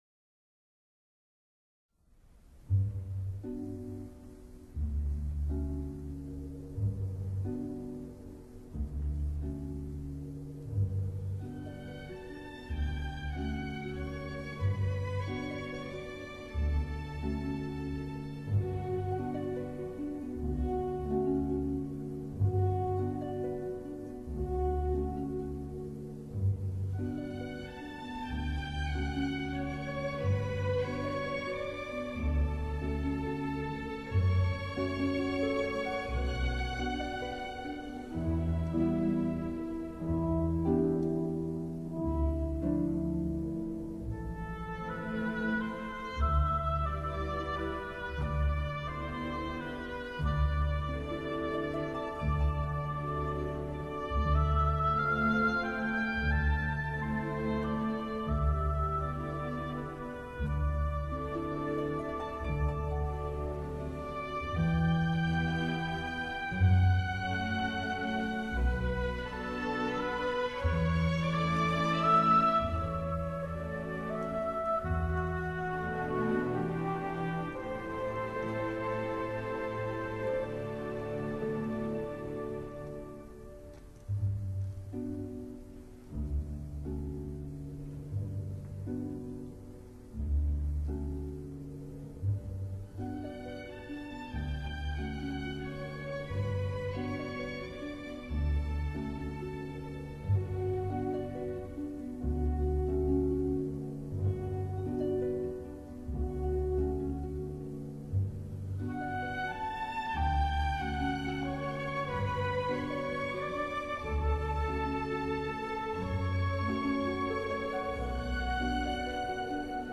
Erik_Satie_-_Gymnopédie_No._1_(Orchestra).mp3